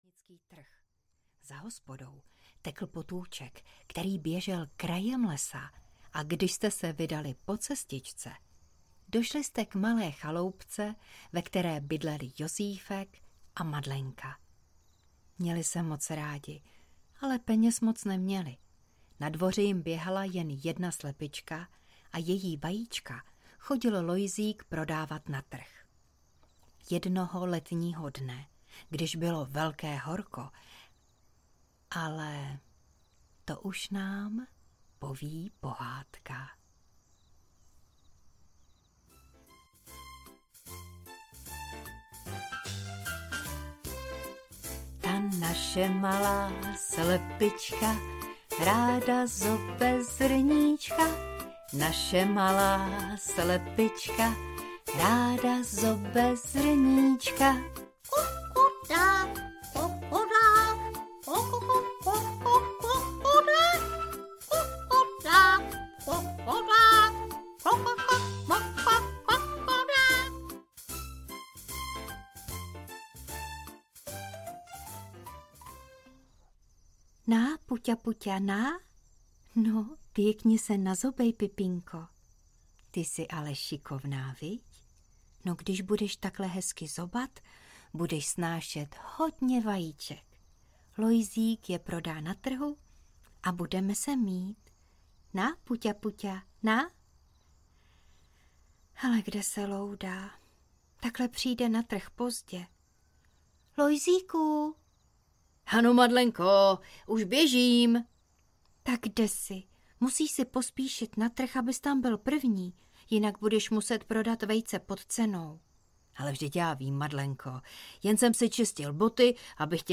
Ukázka z knihy
obusku-z-pytle-ven-audiokniha